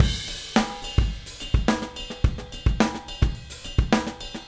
Retro Funkish Beat 02 Crash.wav